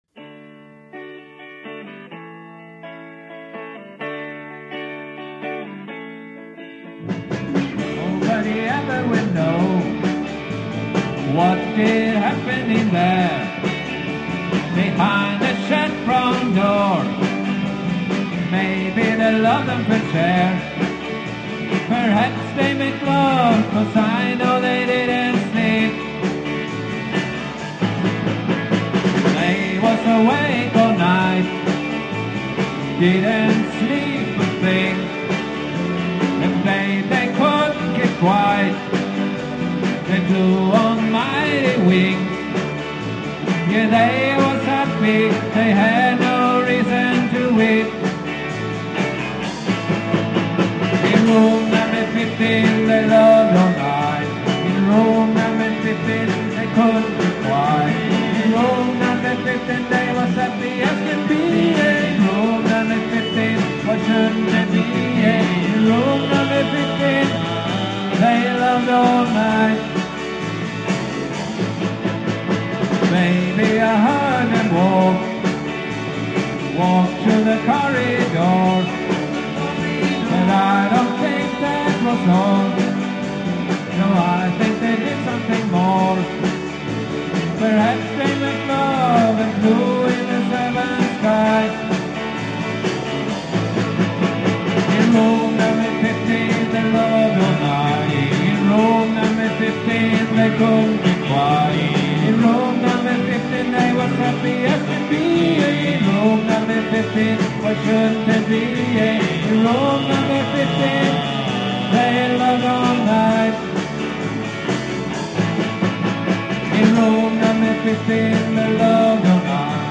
sångare
gitarr
trummor